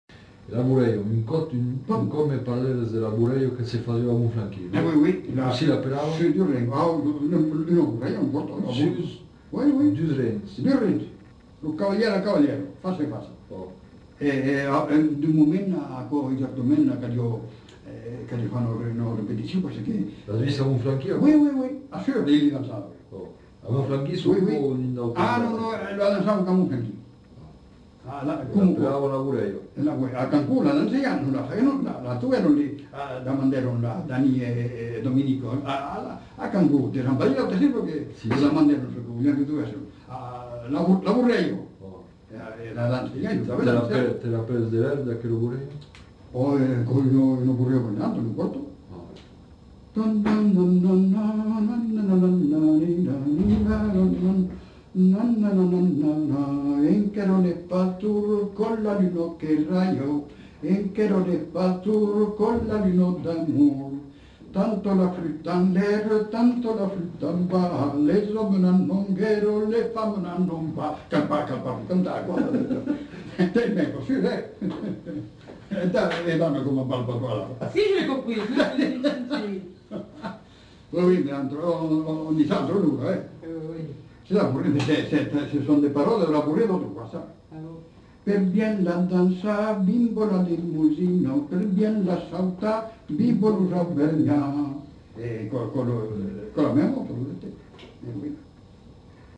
Lieu : Villeneuve-sur-Lot
Genre : chant
Type de voix : voix d'homme
Production du son : fredonné ; chanté
Danse : bourrée
Notes consultables : Il enchaîne plusieurs chants différents.